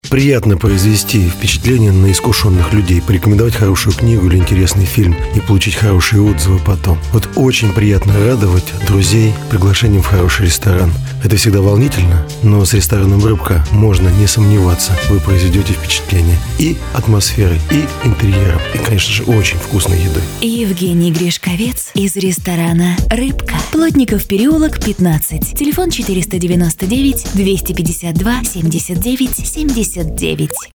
Для рекламы ресторана на радио мы записывали мастера разговорного жанра Евгения Гришковца. Как и в случае с Тиной Канделаки, работать приходилось прямо на рабочем месте Евгения – в театре.
Аудиореклама для рыбного ресторана «Рыбка» от Ginza Project